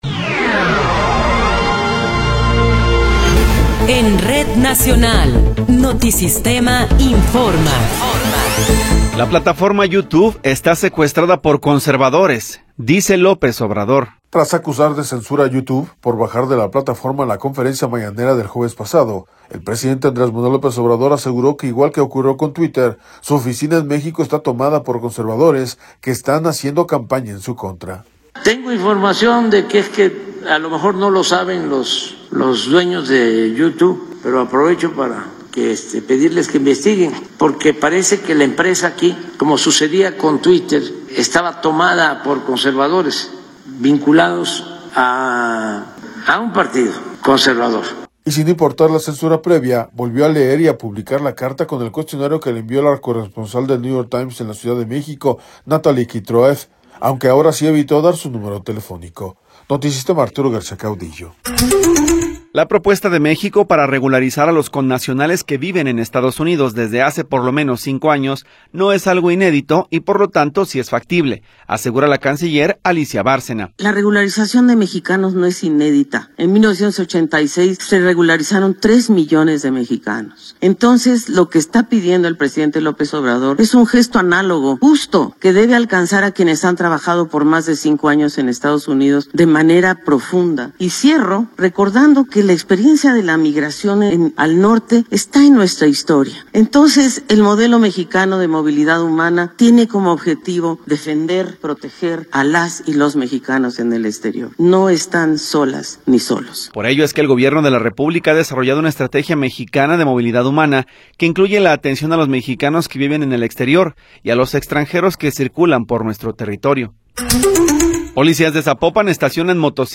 Noticiero 11 hrs. – 26 de Febrero de 2024